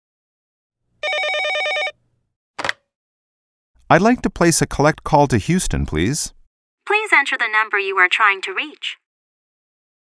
實境對話